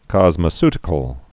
(kŏzmə-stĭ-kəl)